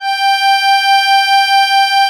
MUSETTESW.14.wav